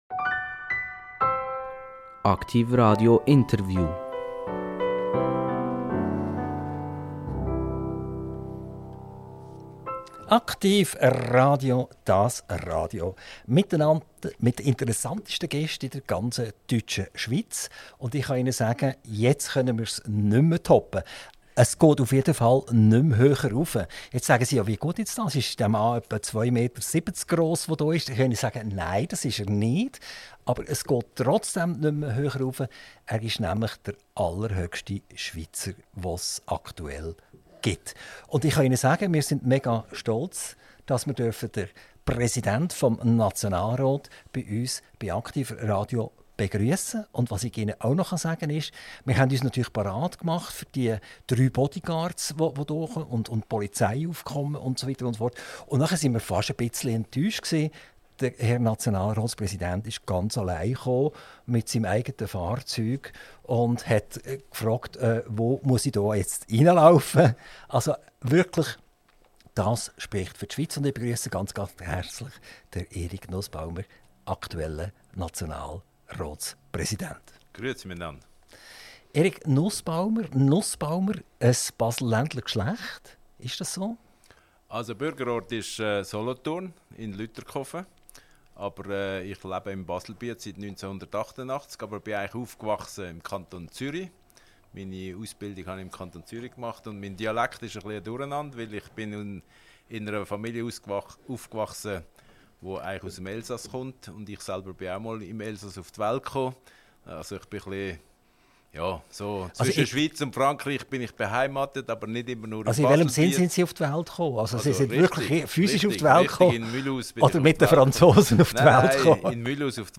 INTERVIEW - Eric Nussbaumer - 25.11.2024 ~ AKTIV RADIO Podcast